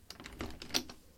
监狱门
描述：用智能手机录制
标签： 教堂 教堂 金属制品 满足 莱昂 普埃尔塔 安洁莉卡 现场记录
声道立体声